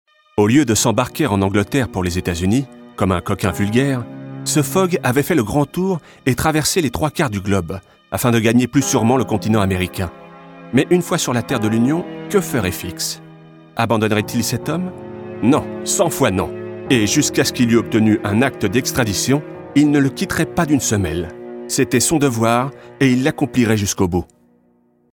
French male voice talent